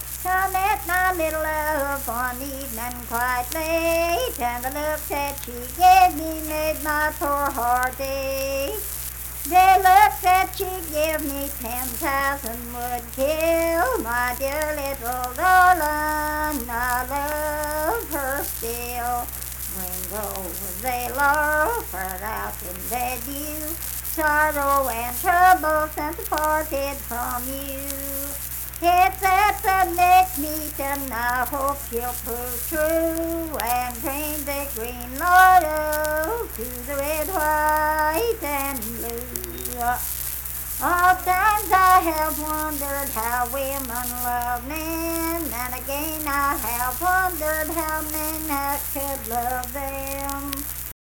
Unaccompanied vocal music performance
Verse-refrain 2(2-4) & R(4).
Voice (sung)